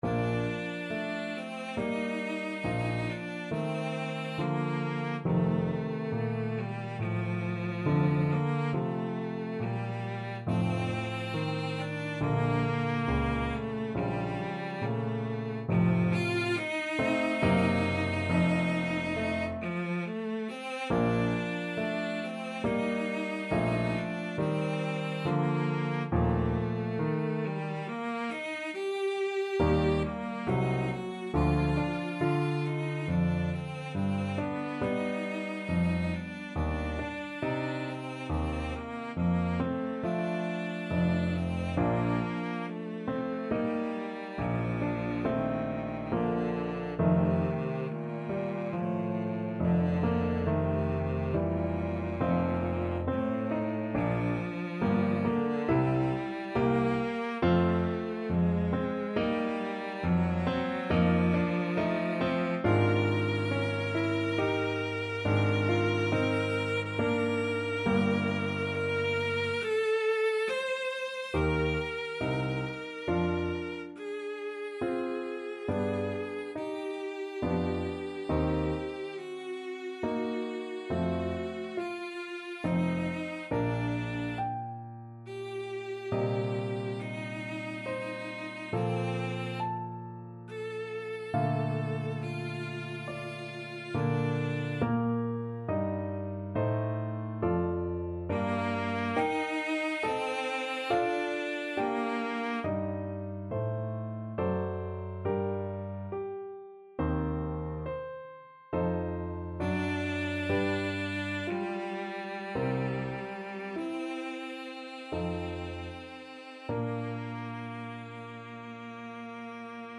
Classical Brahms, Johannes Piano Concerto No.2, Op. 83, Slow Movement Main Theme Cello version
Bb major (Sounding Pitch) (View more Bb major Music for Cello )
Andante =c.84 =69
6/4 (View more 6/4 Music)
Classical (View more Classical Cello Music)